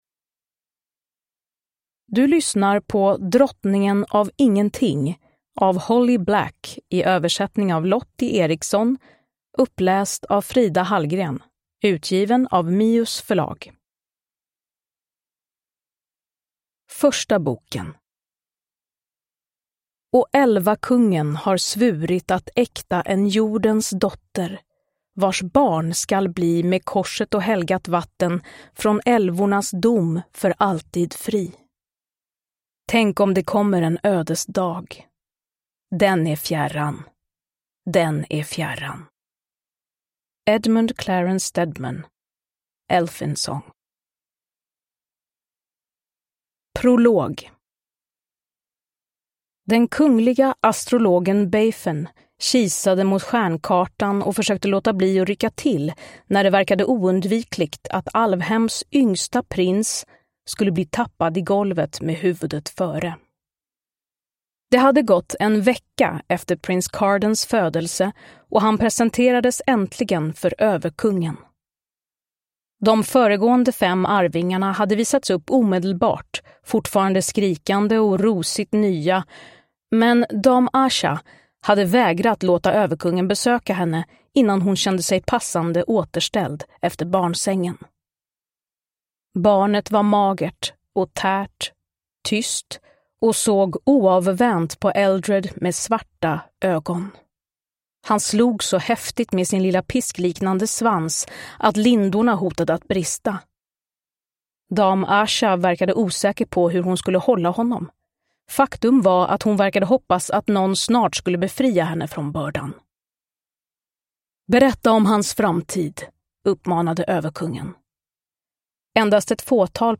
Drottningen av ingenting – Ljudbok
Uppläsare: Frida Hallgren